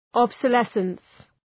Προφορά
{,ɒbsə’lesəns}